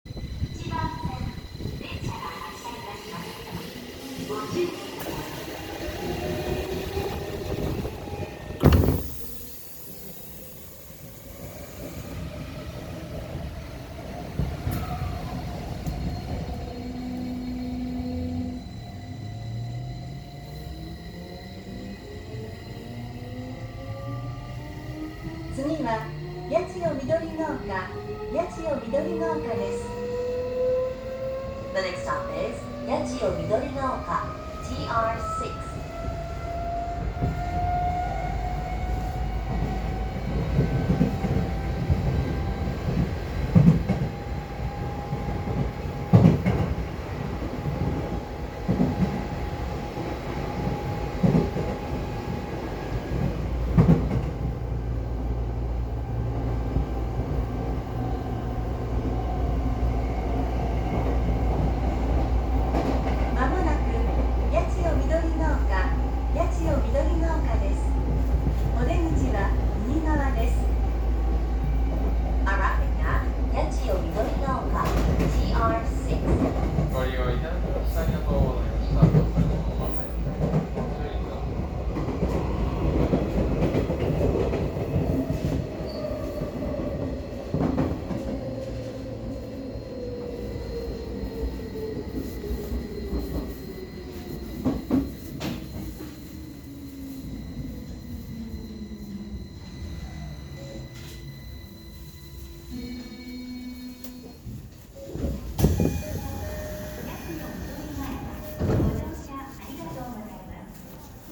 ・05系（114〜118F：更新車）走行音
16000系や1000系に準じたDDMの走行音で、最初の低音の部分が随分響くような気がします。ドアチャイムはやっぱりJRタイプのものに交換されてしまっています。